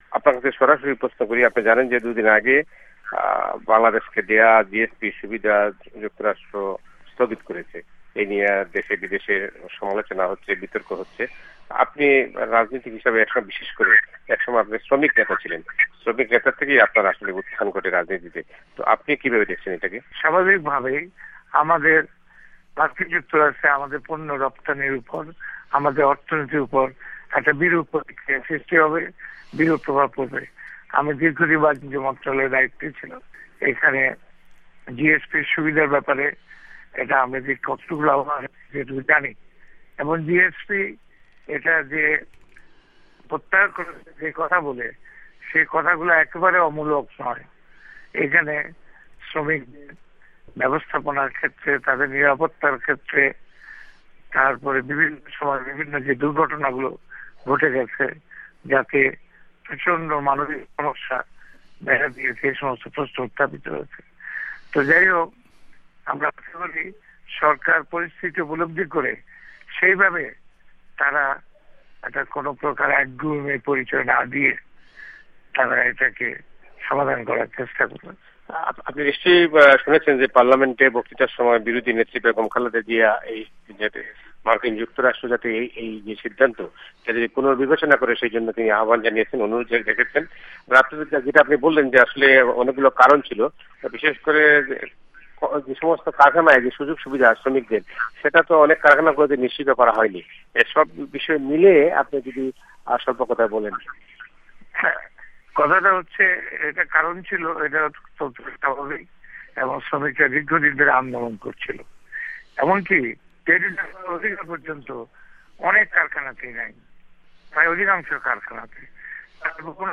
যুক্তরাষ্ট্র যে সাময়িকভাবে বাংলাদেশের জি এস পি সুবিধা স্থগিত করেছে , তা নিয়ে আমরা কথা বলি বাংলাদেশের জাতিয় পার্টির অন্যতম প্রিসিডিয়াম সদস্য , বর্ষিয়ান রাজনীতিক , এক সময়কার সাড়া জাগানো শ্রমিক নেতা কাজি জাফর আহমদের সঙ্গে । আন্তর্জাতিক টেলি কনফারেন্স লাইনে তাঁর সঙ্গে কথা বলেন
আমরা সে কথোপকথন রেকর্ড করি এখানে ওূয়াশিংটন স্টুডিওতে ।